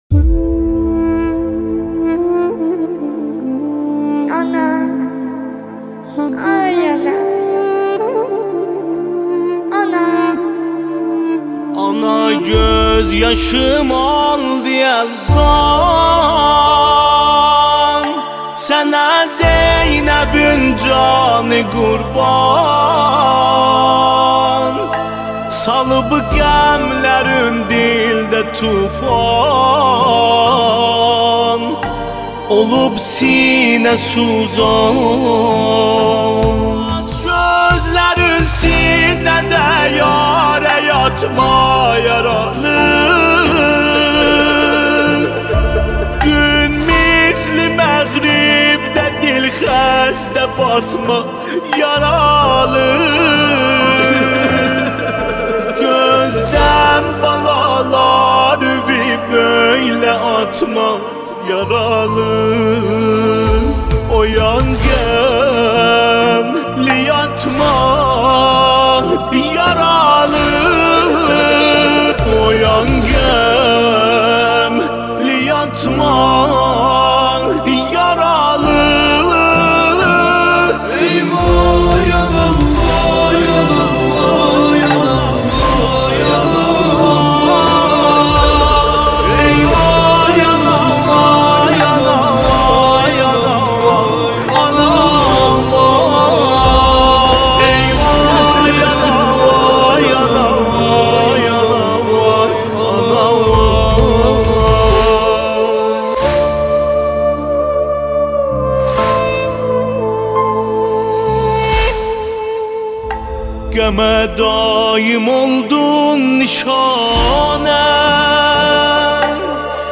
دانلود مداحی ترکی